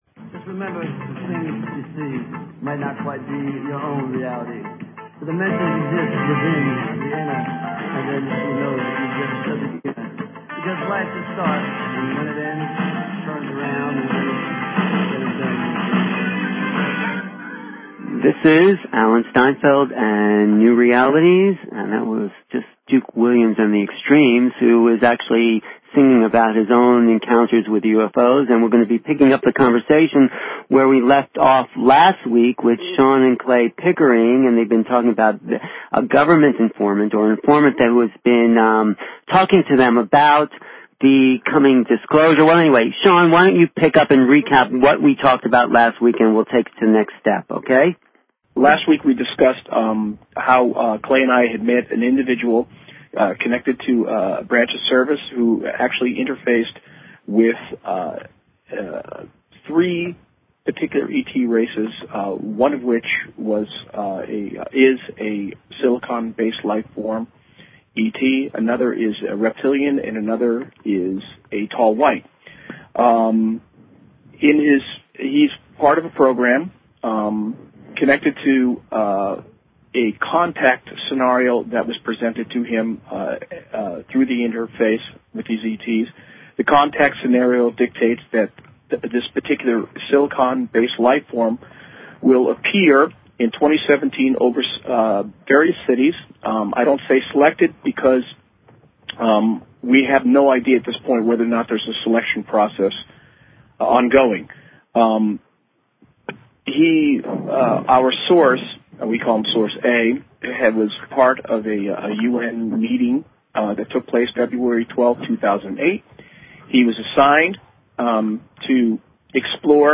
New Realities Talk Show